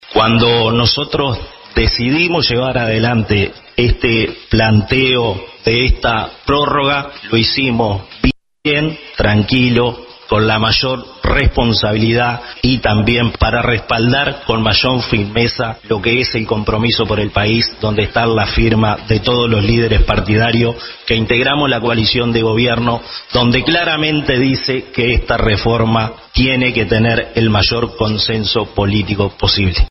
En la sesión de la Cámara de Diputados, el legislador cabildante Alvaro Perrone explicó los motivos de la solicitud de la prórroga